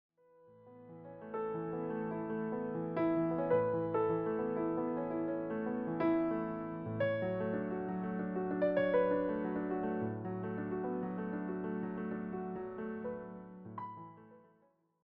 presented in a smooth piano setting.
steady, easygoing tone